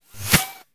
bullet_flyby_04.wav